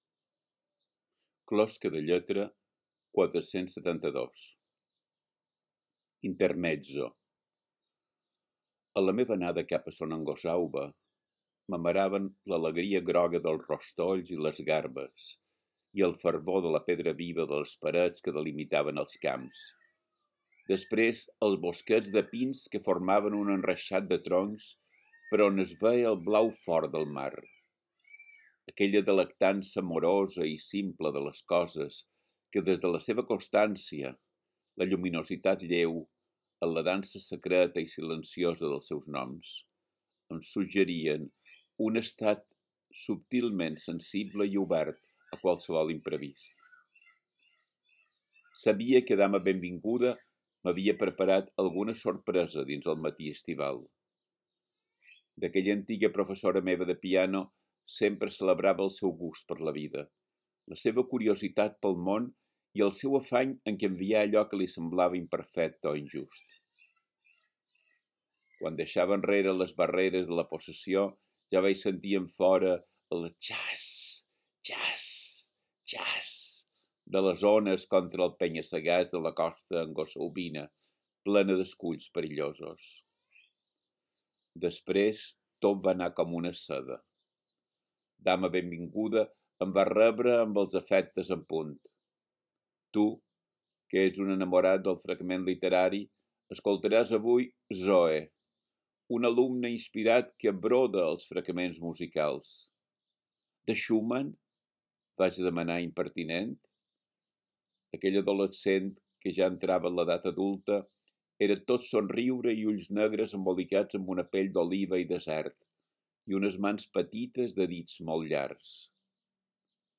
Podeu escoltar el text recitat per Biel Mesquida mateix: